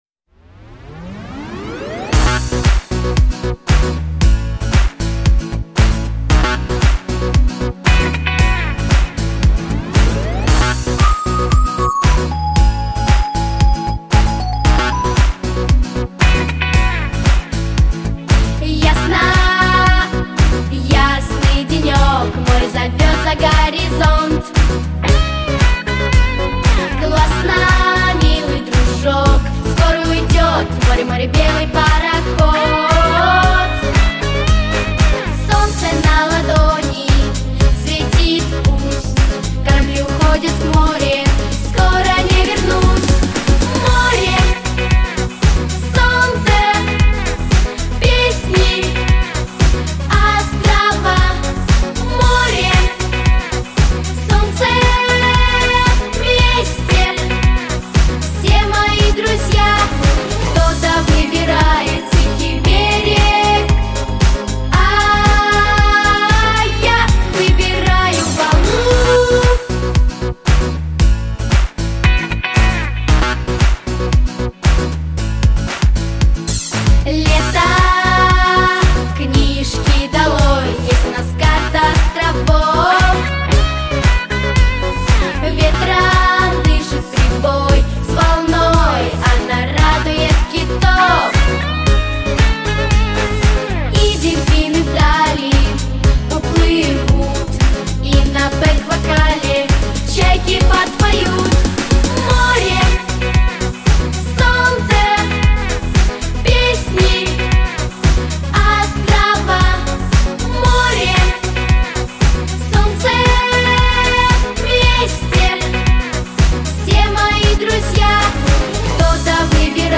• Категория: Детские песни
Детский эстрадный ансамбль